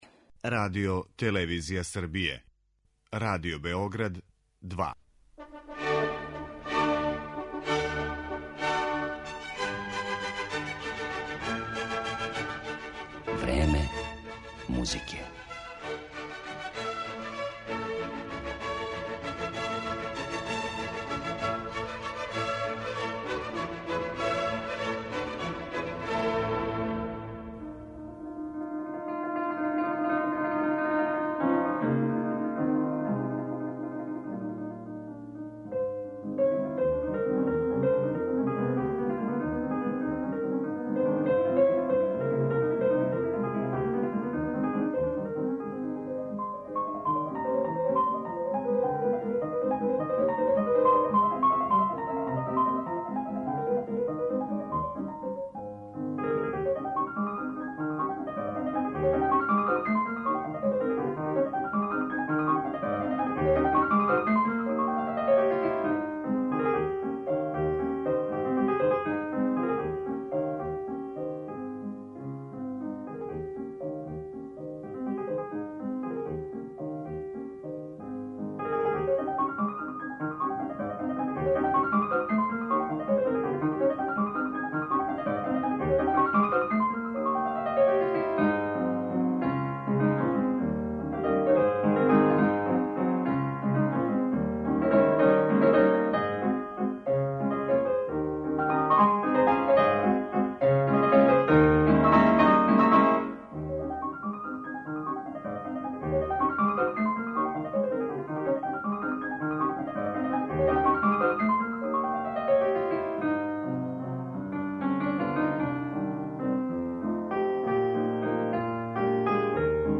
Она је издвојила делове предавања